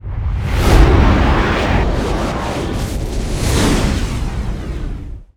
warpend.wav